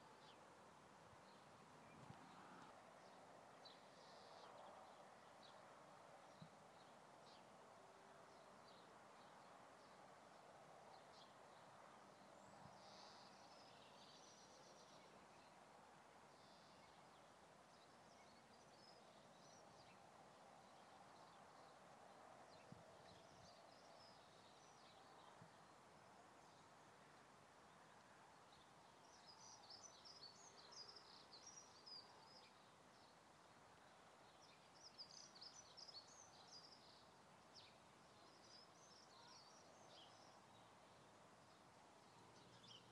Enjoying the birds singing, sun shining and the view